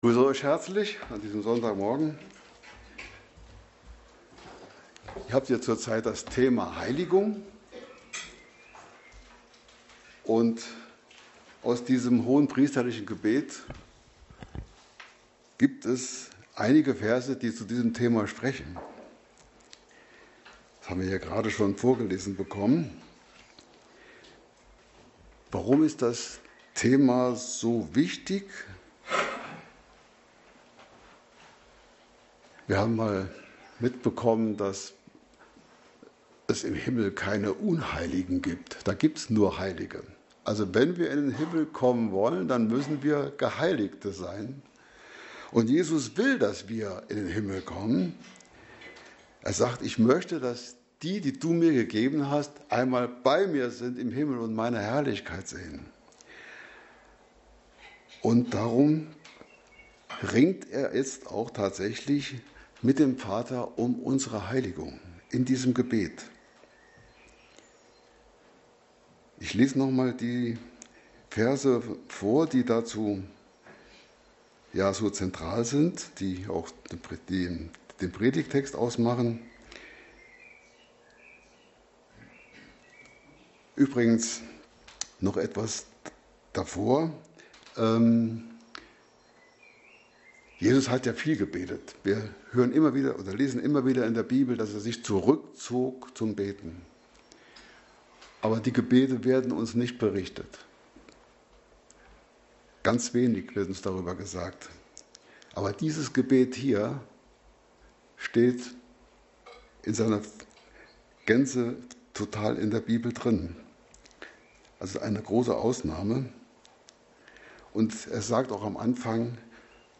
Passage: Johannes 17,16-19 Dienstart: Predigt